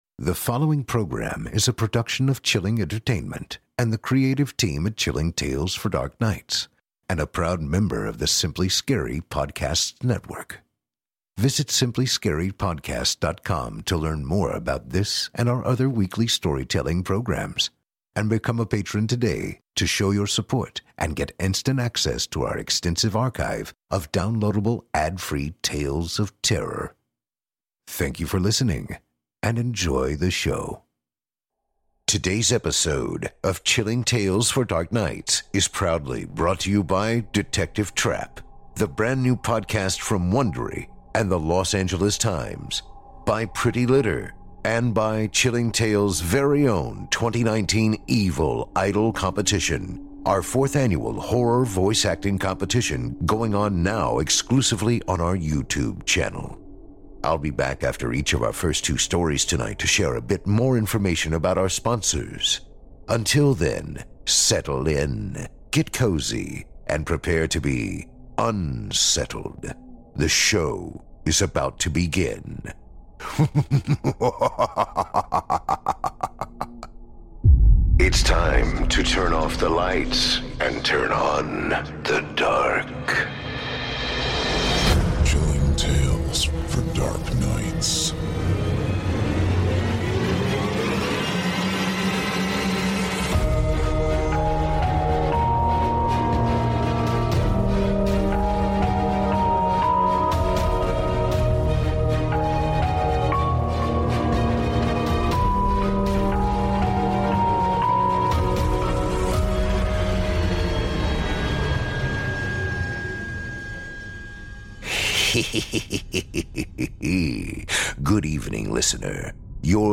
On tonight’s program, we invite you to leave behind your safe reality, and descend with us into the frightening depths of the most terrifying imaginations, with audio adaptations of three rounds of frightening fiction, about psychic sleight-of-hand, paranormal parties, and life-changing choices.